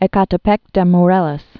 (ĕ-kätä-pĕk dĕ mō-rĕlōs)